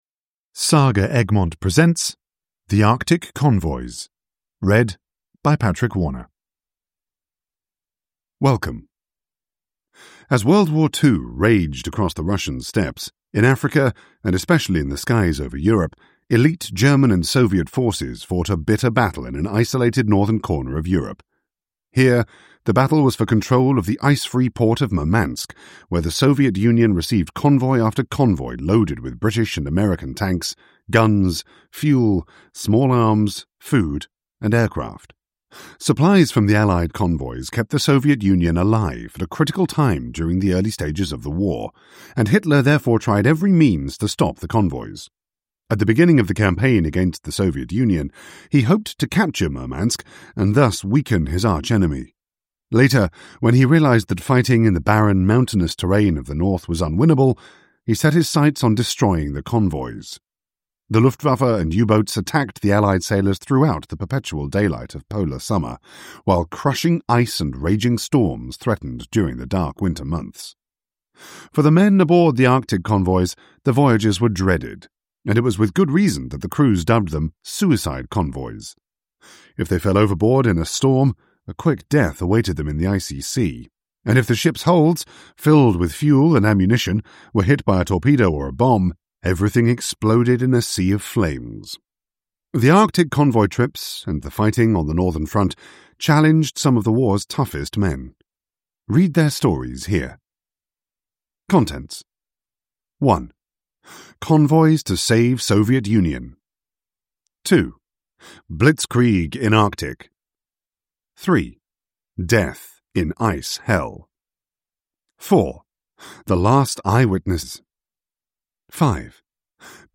The Arctic Convoys – Ljudbok